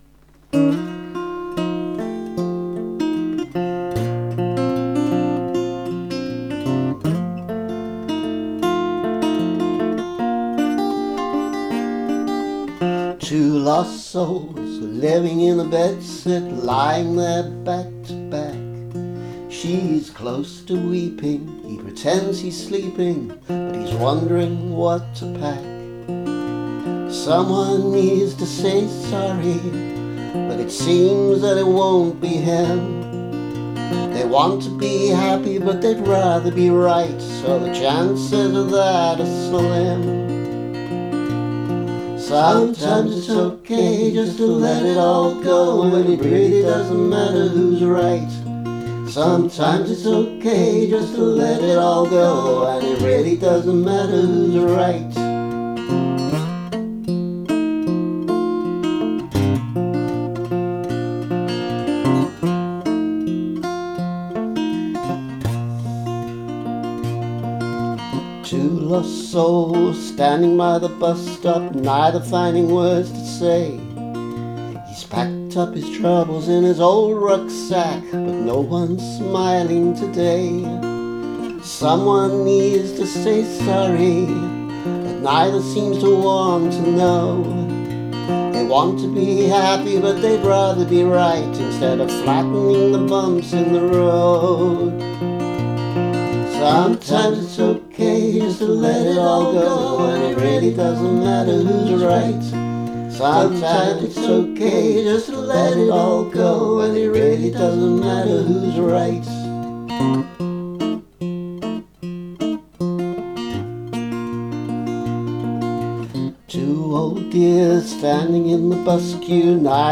I’m planning to include a cleaner (I mean better recorded, not less obscene!) version of this on a forthcoming album called ‘Kitsch and Canoodle’, but this is probably most of the way there.